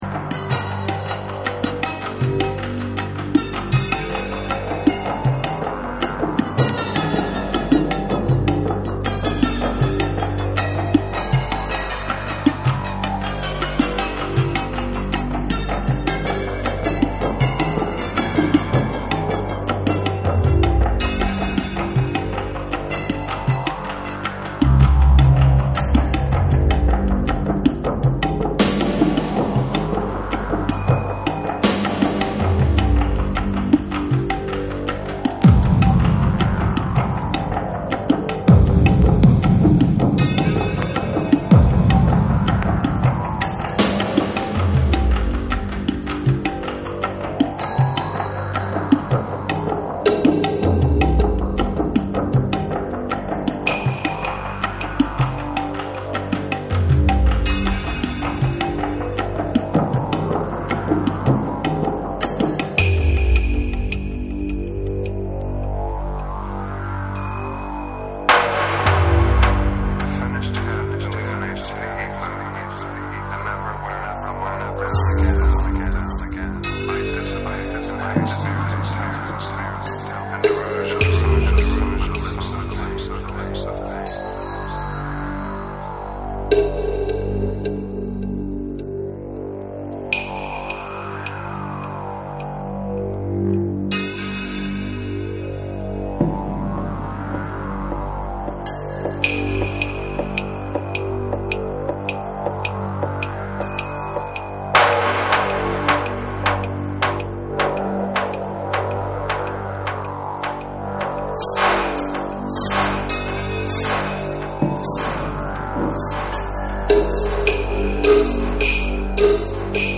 タブラのリズムを取り入れた、サイケデリック空間へと引き込まれるアンビエント・ナンバー